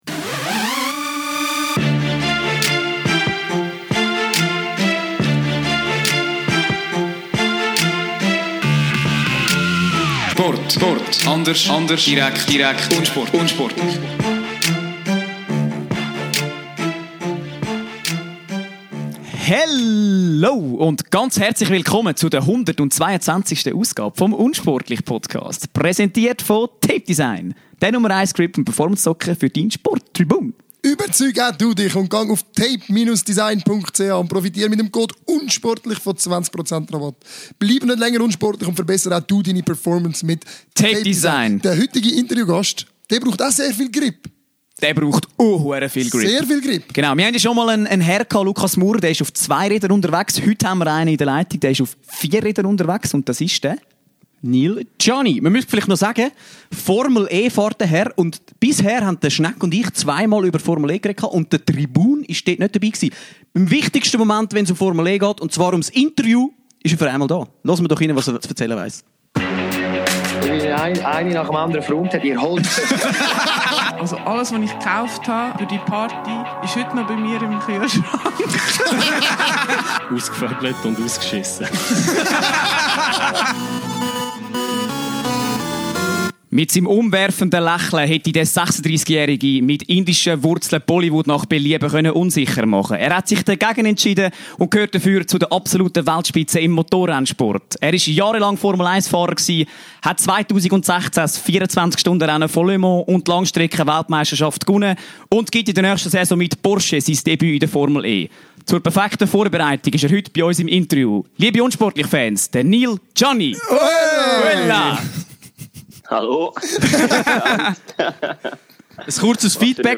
Wir sprechen mit Neel über seine Anfänge auf der Kartbahn, wie er Lewis Hamilton zum Weltstar machte, seinen Temporekord auf der Autobahn, darüber das Rennfahrer eben doch Athleten sind und dass man als Rennfahrer nichts verdient – vielmehr bekommt man alles gratis! Sodann wagen wir einen Ausblick in die neue Formel E-Saison (eigentlich MarioKart für die grossen Buben), in welcher er für den Rennstall Porsche im nächsten Jahr an den Start gehen wird.